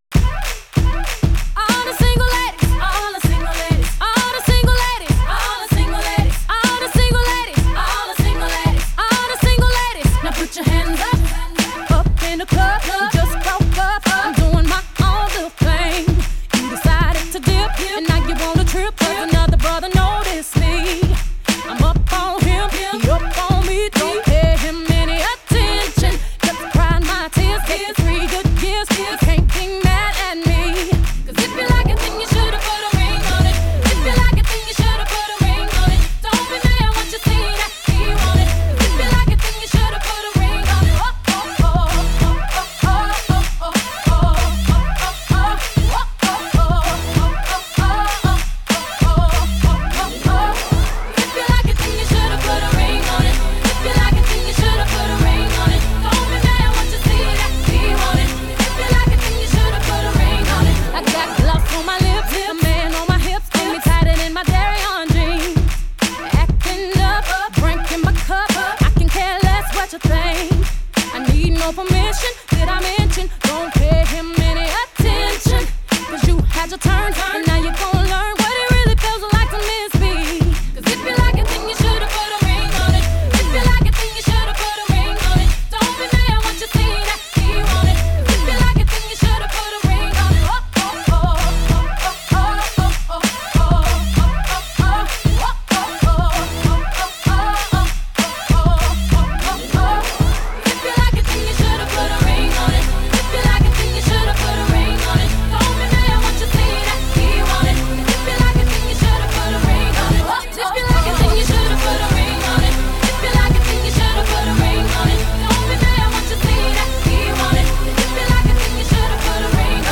BPM97